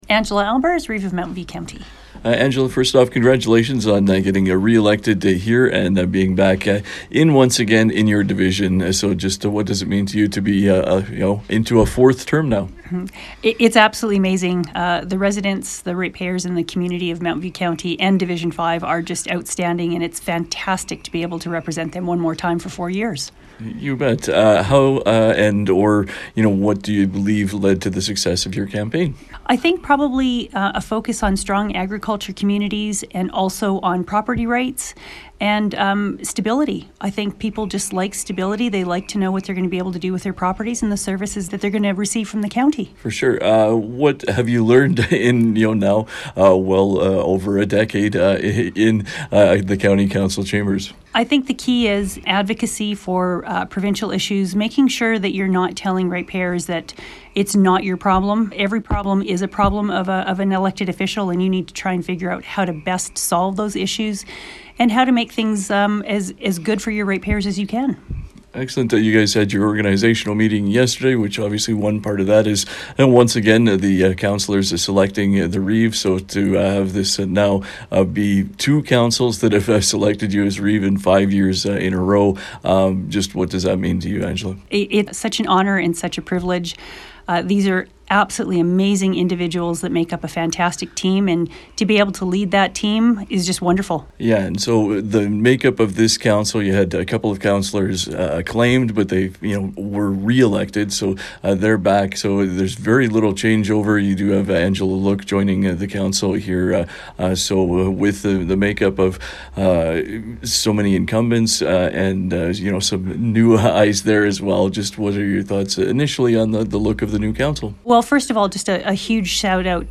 Listen to 96.5 The Ranch’s conversation from October 30th with Angela Aalbers.